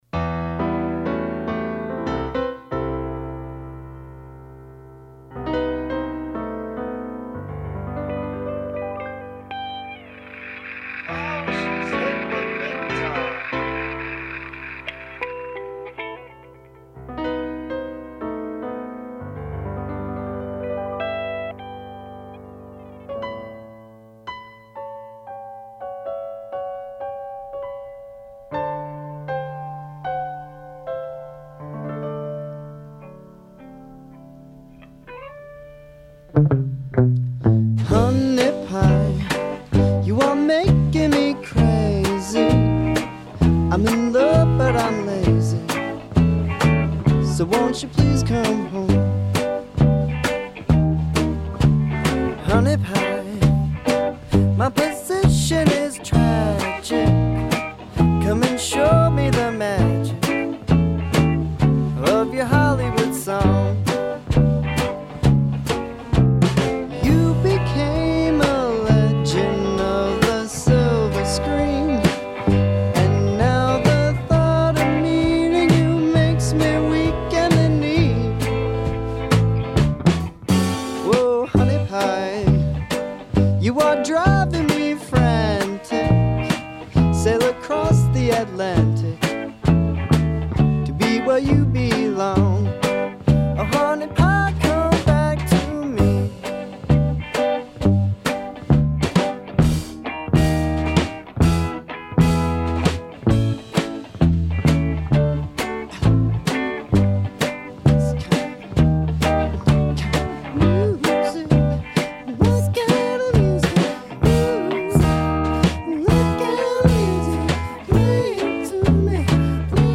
For this session, we simulataneously recorded both Analog and Digital.